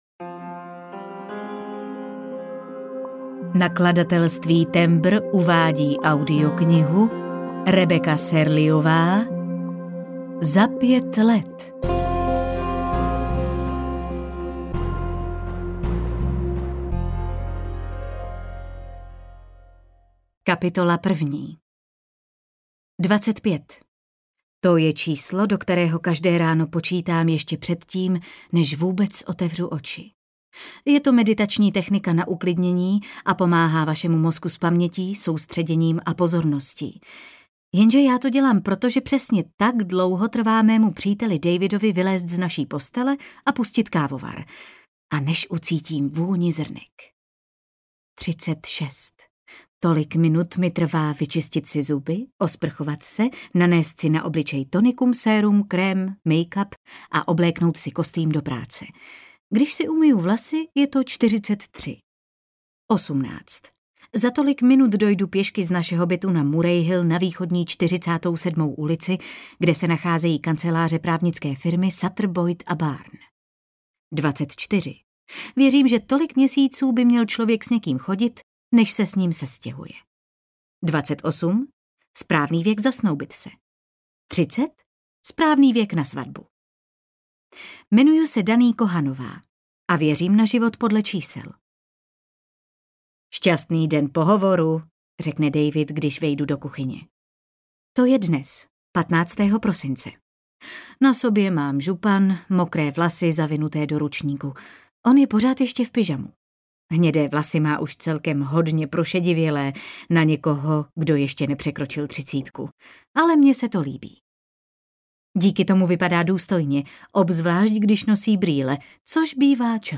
Za pět let audiokniha
Ukázka z knihy